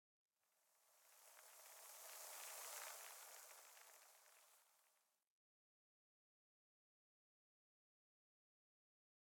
Minecraft Version Minecraft Version snapshot Latest Release | Latest Snapshot snapshot / assets / minecraft / sounds / block / sand / sand10.ogg Compare With Compare With Latest Release | Latest Snapshot
sand10.ogg